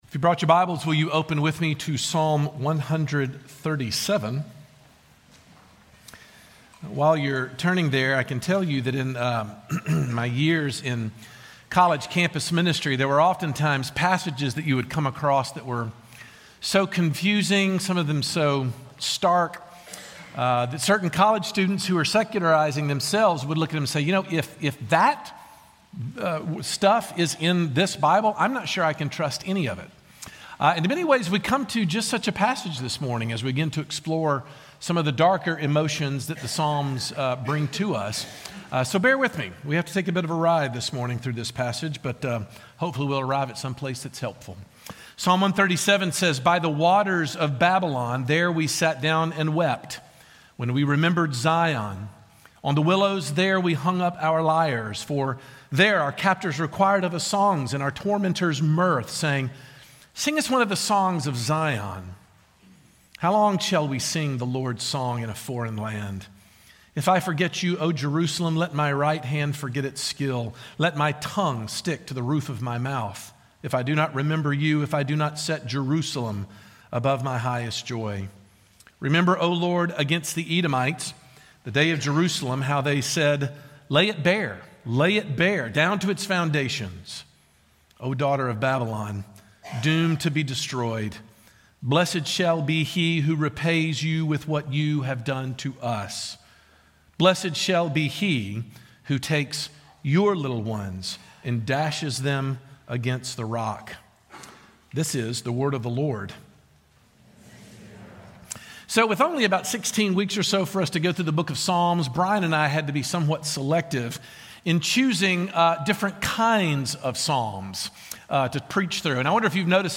But there is a godly way to manage that emotion. And Psalm 137 provides a vivid, if not disturbing, look into that very thing. Sermon Points